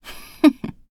文件 文件历史 文件用途 全域文件用途 Cyrus_fw_02.ogg （Ogg Vorbis声音文件，长度0.9秒，125 kbps，文件大小：14 KB） 源地址:游戏语音 文件历史 点击某个日期/时间查看对应时刻的文件。